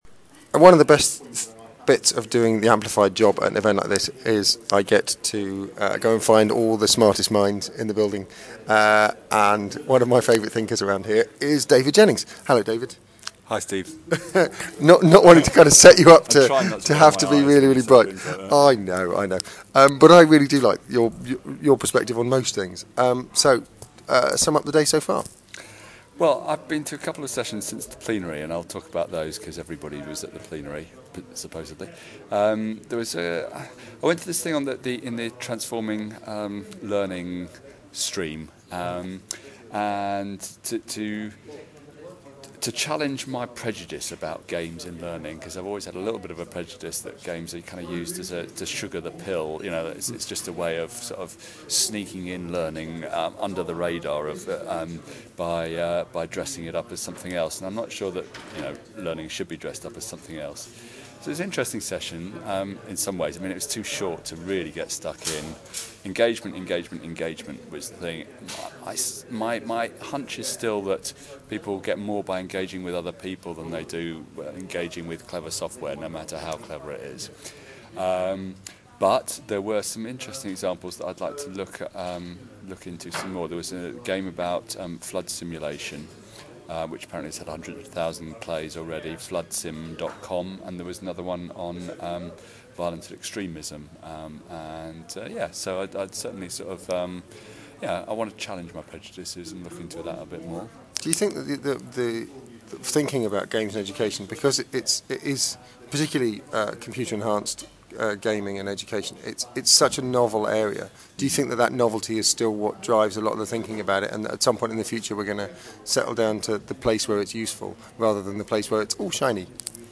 The Amplified team are today at Reboot Britain - a one day conference looking at almost every area of civic life in the UK - education, politics, the economy, the environment, public services - and how emerging technologies can resource, affect and change the way we live.